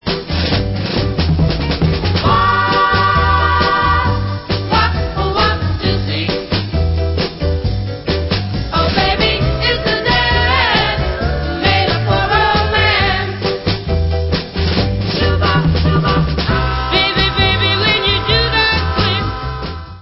sledovat novinky v oddělení Dance/Soul